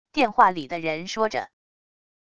电话里的人说着wav音频